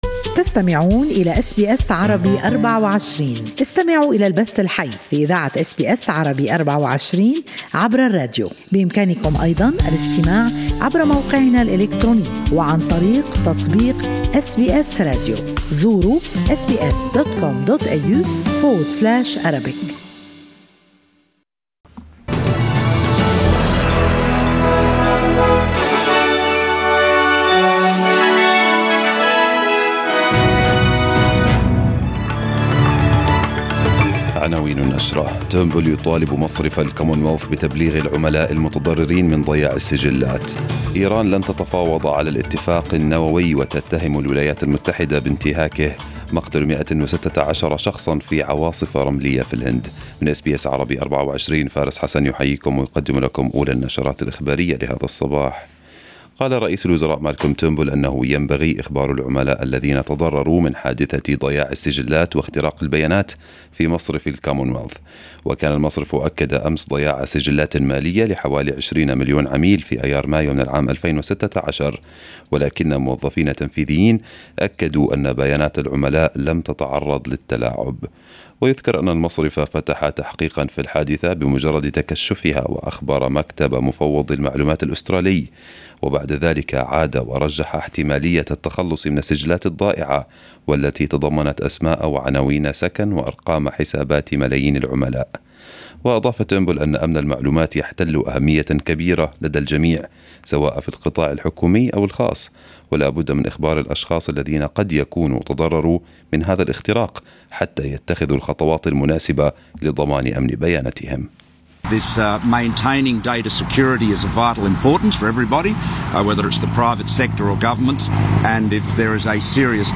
Arabic News Bulletin 04/05/2018